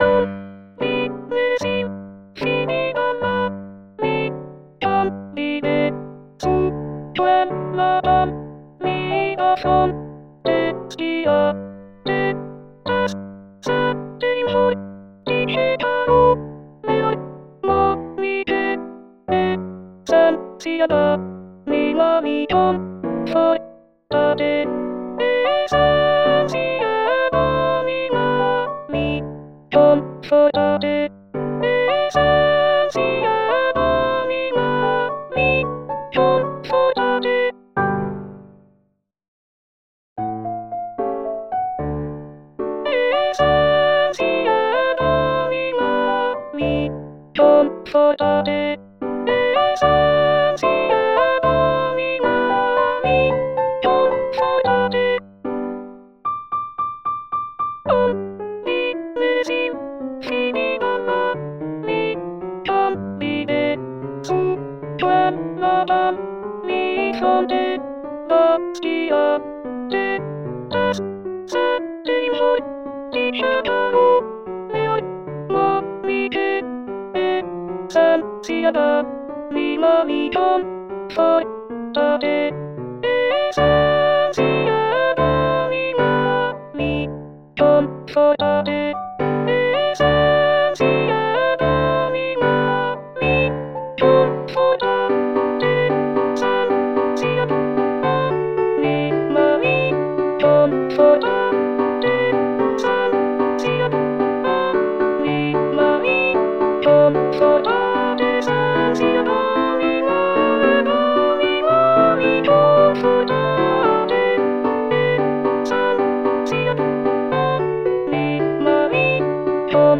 SOPRANES
sop-coro-e-ballabile-reduction.mp3